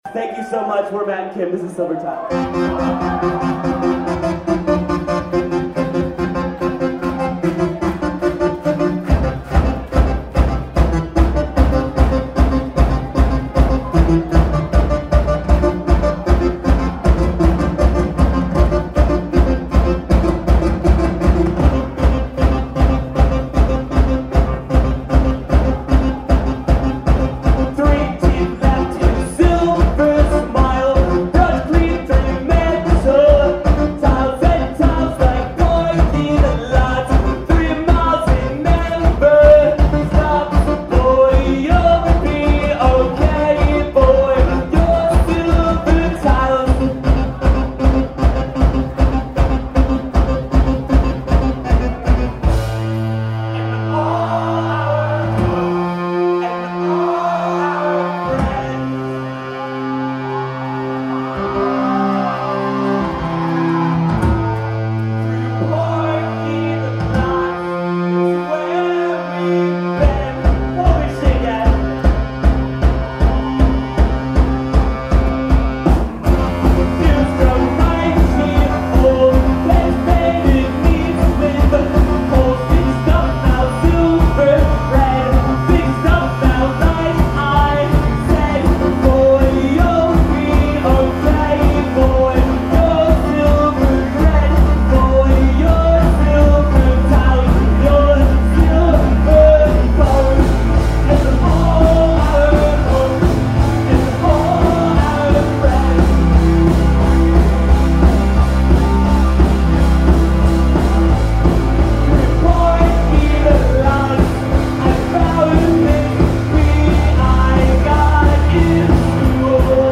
drums
keyboards